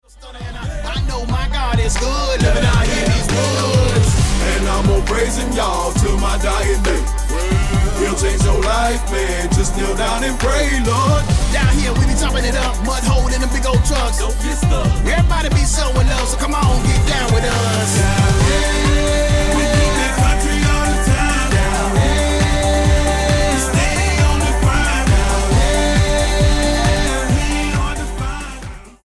The band fusing old spirituals, hip-hop and bluegrass
Style: Hip-Hop